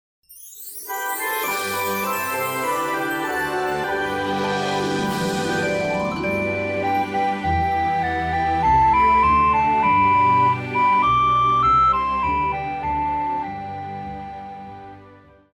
高音直笛
樂團
聖誕歌曲,聖歌,教會音樂,古典音樂
鋼琴曲,演奏曲
獨奏與伴奏
有主奏
有節拍器